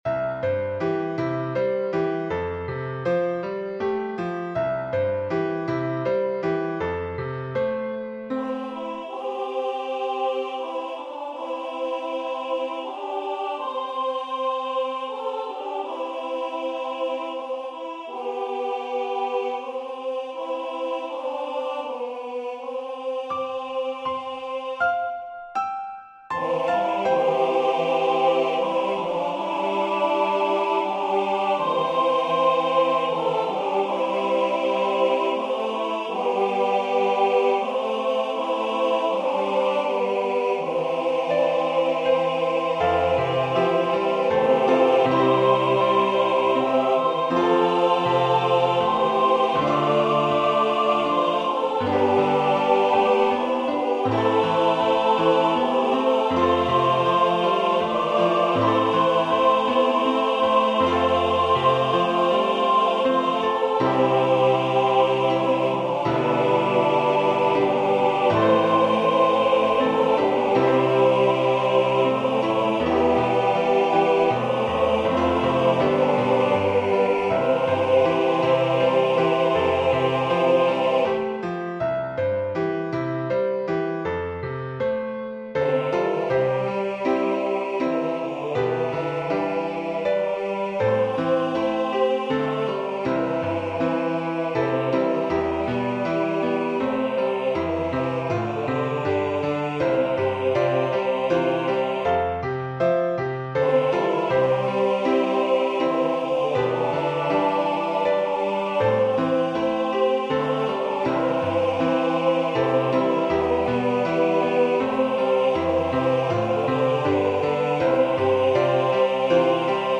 Voicing/Instrumentation: SATB We also have other 17 arrangements of " My Shepherd Will Supply My Need ".